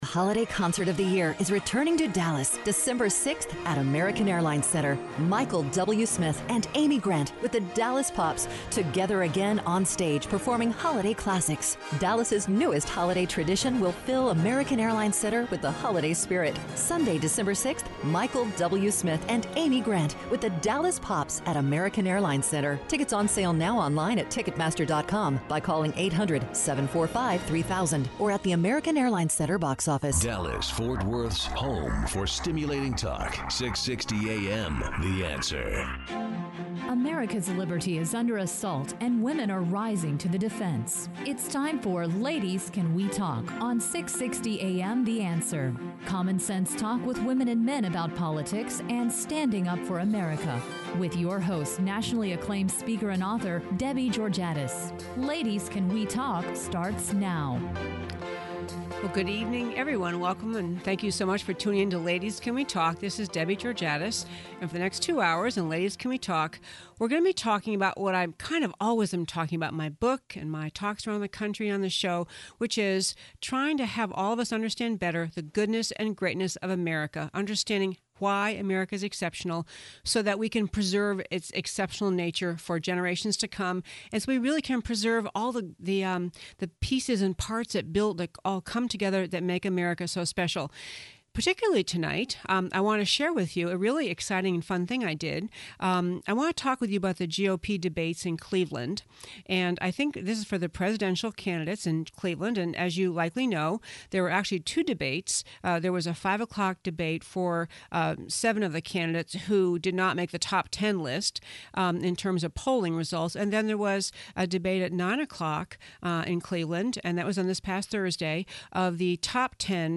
The Cleveland Debate and RedState Conference; Allen West in Studio
Listen to the podcast from the first hour of our August 9th show on 660AM.